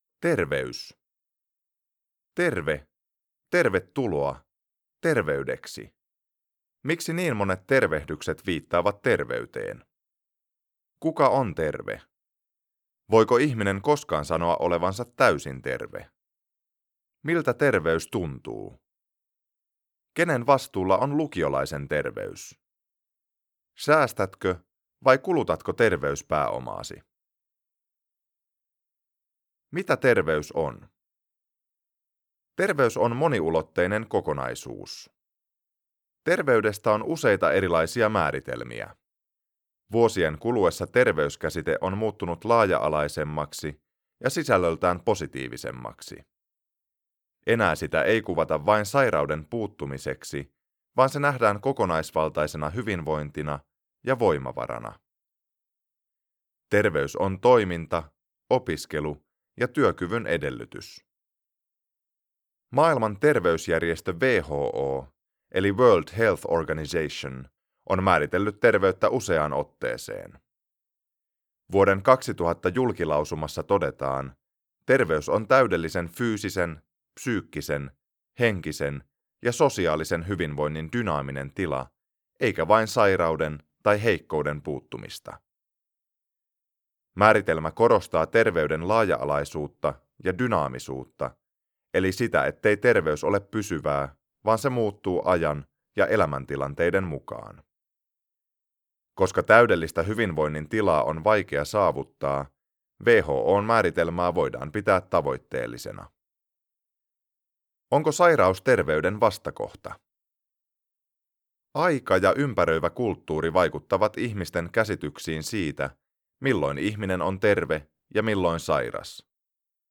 Terve! 1 Äänikirja kpl 1.